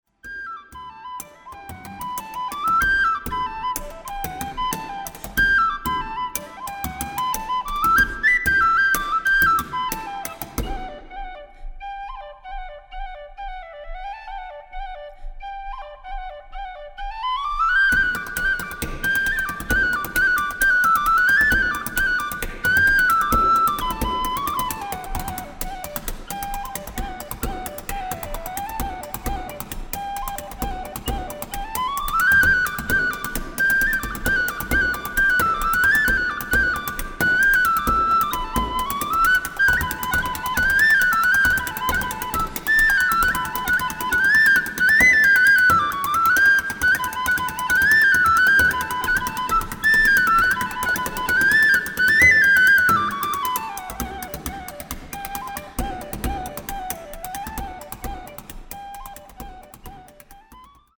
penny whistle & bodhran